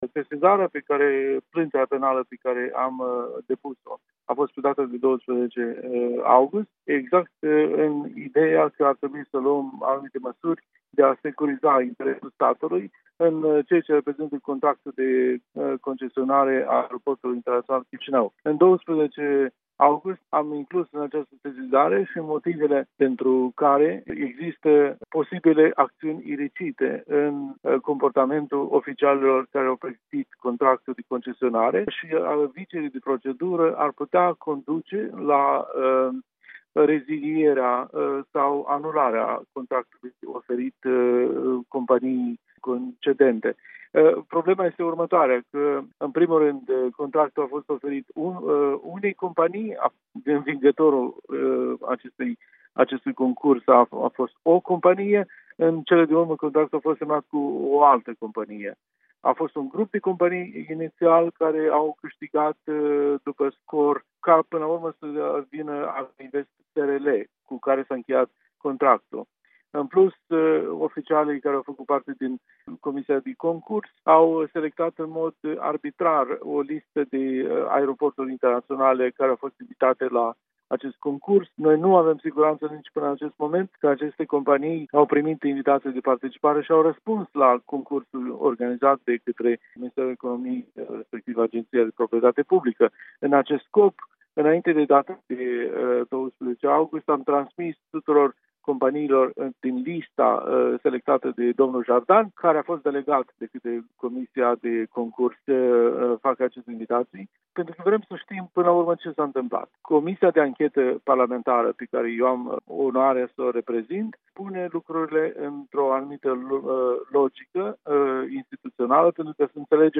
Un interviu cu președintele Comisiei de anchetă din Parlament despre perspectivele cazului concesionării Aeroportului Internațional Chișinău..